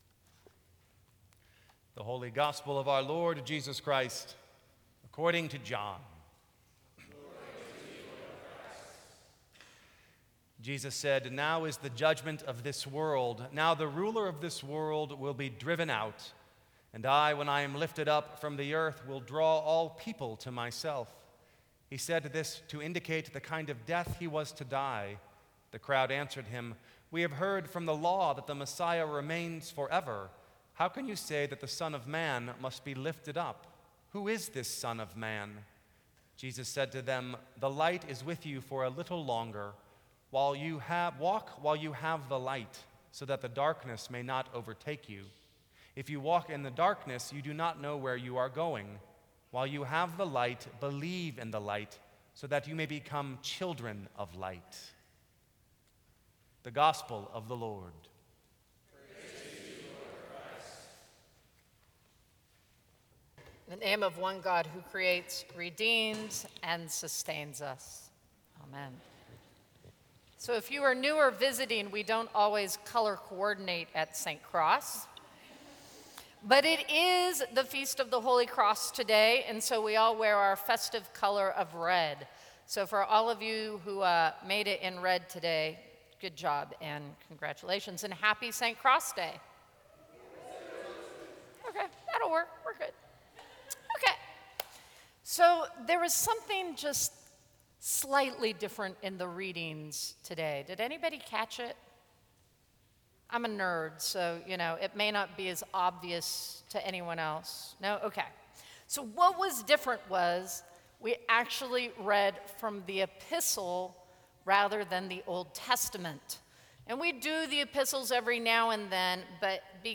Sermons from St. Cross Episcopal Church September 14, 2014.